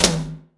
ROOM TOM1B.wav